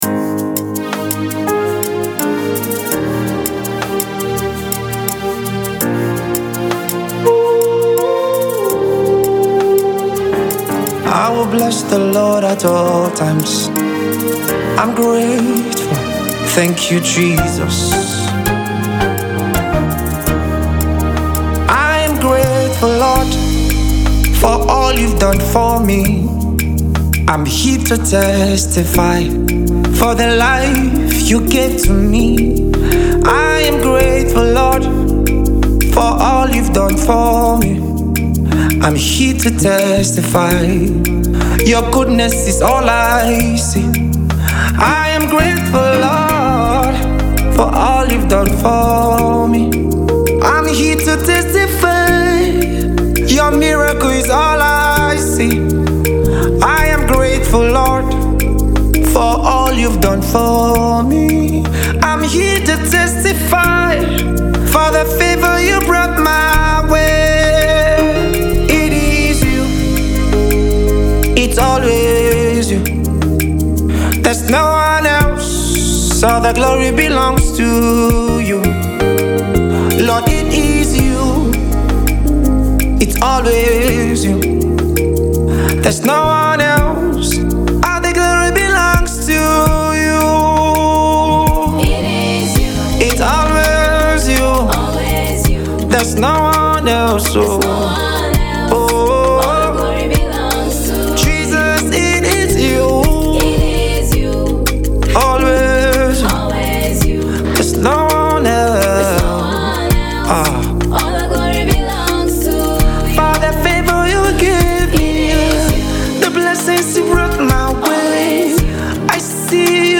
This powerful and spirit-filled worship song
soul-stirring worship song